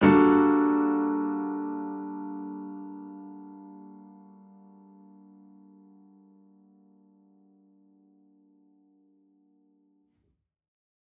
Index of /musicradar/gangster-sting-samples/Chord Hits/Piano
GS_PiChrd-Gmin9maj7.wav